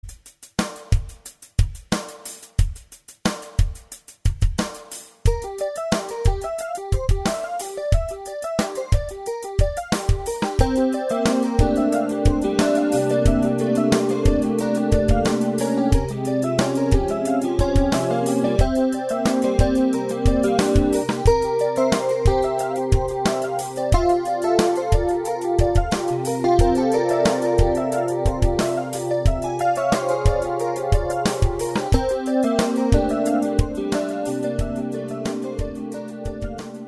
♪2014-8-2(土）〜3(日）　夏合宿　白樺 湖畔　ラ・シャンブル　♪
夜間はお酒を飲みながら大セッション大会となりました。